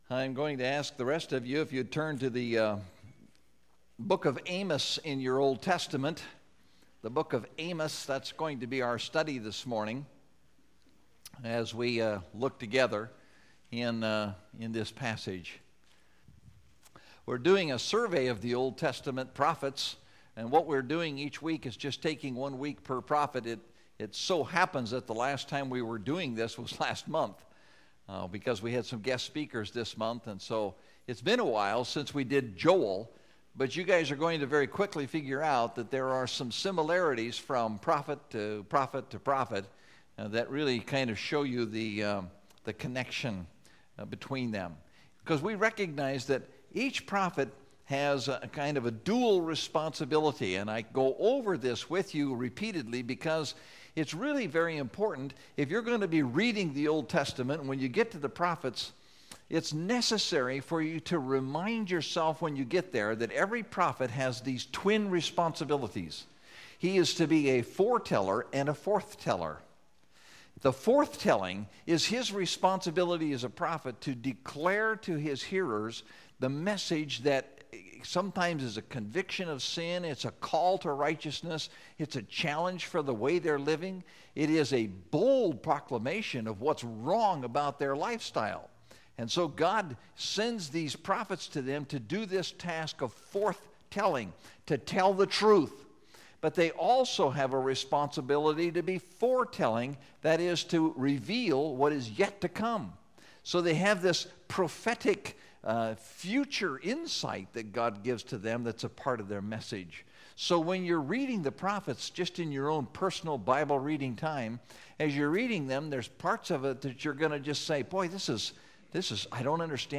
Profiles of the Prophets – Amos – Mountain View Baptist Church